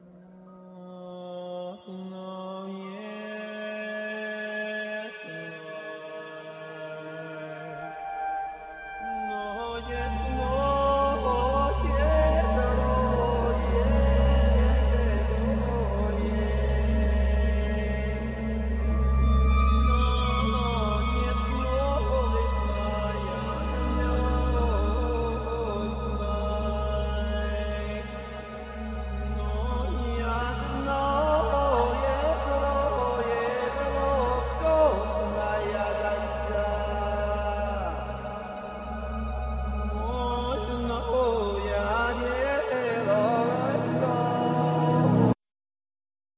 Keyboards,Synthesizers,Percussion,Vocal